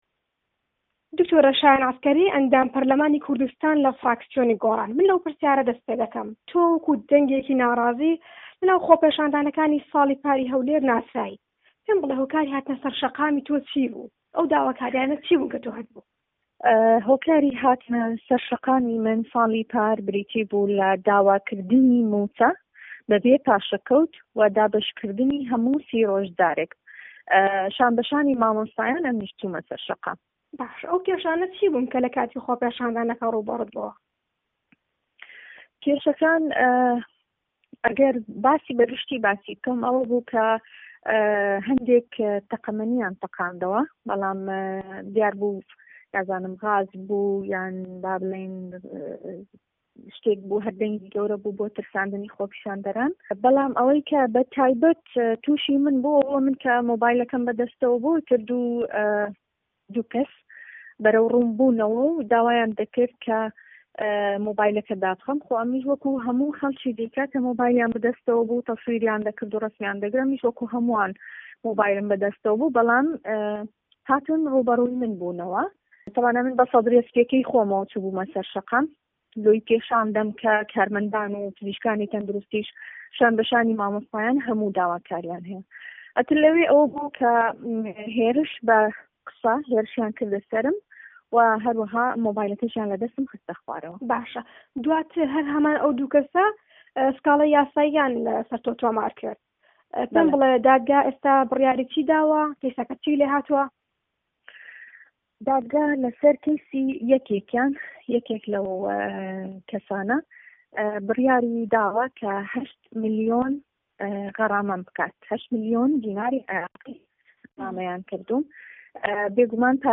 وتووێژ لەگەڵ د. شایەن عەسکەری